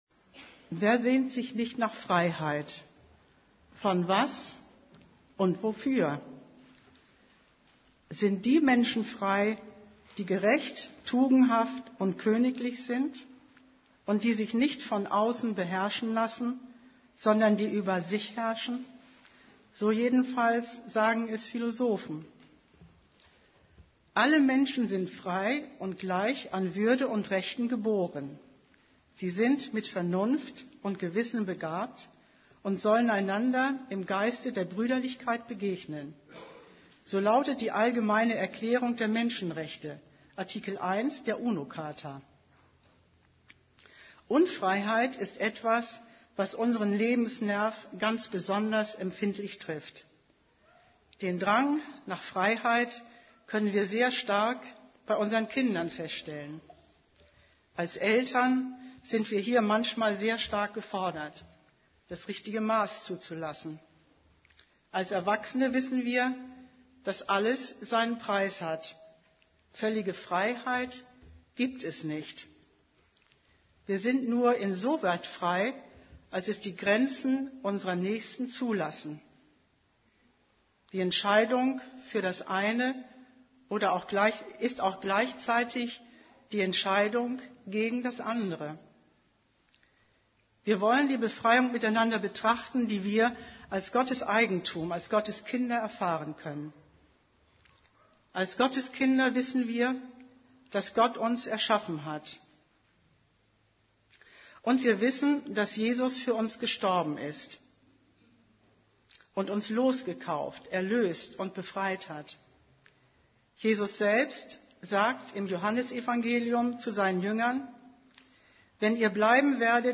> Übersicht Predigten Zum Leben befreit Predigt vom 09.